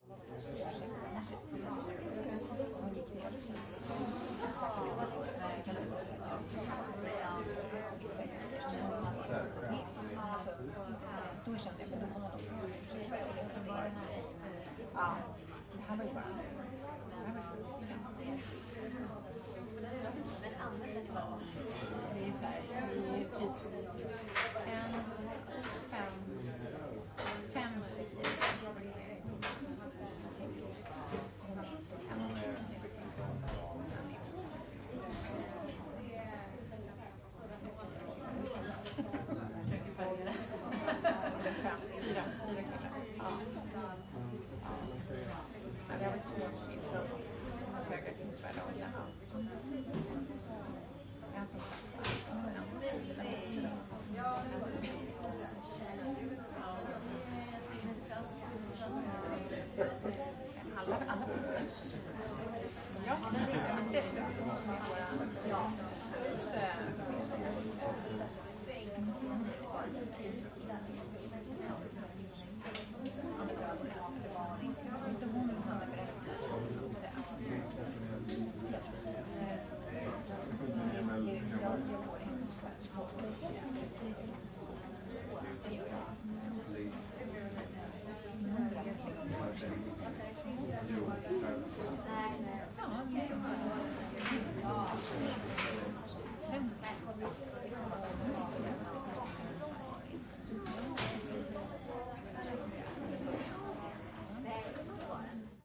描述：用餐
Tag: 餐厅 商场 购物中心 沃拉